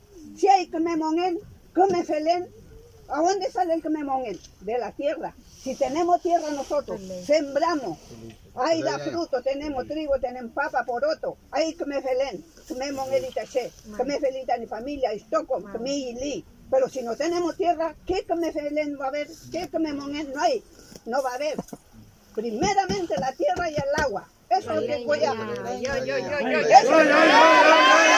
El pasado lunes 22 de febrero la Machi Francisca Linconao fue invitada a participar del Xawvn Kvzaw en el marco del proceso constituyente, convocado por el histórico Parlamento Mapunche de Koz-koz en el territorio de Panguipulli.